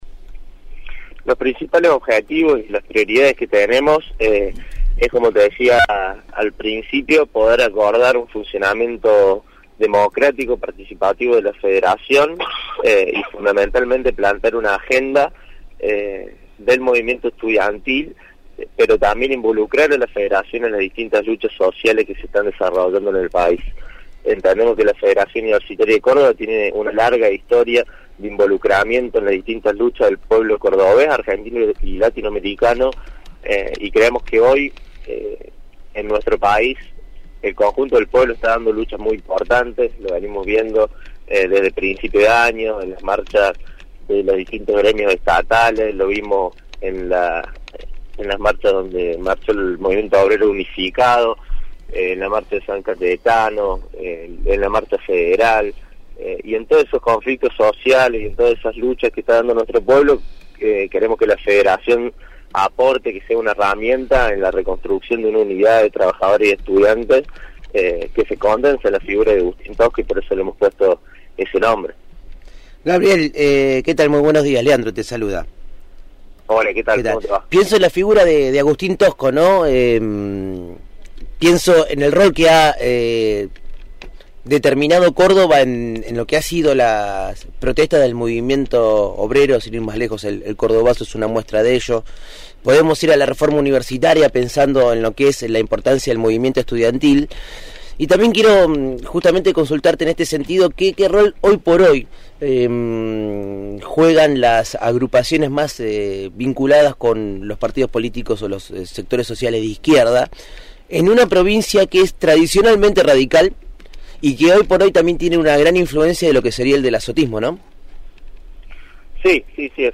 dialogó con el equipo de «El hormiguero» sobre el triunfo del “Frente de Unidad Agustín Tosco”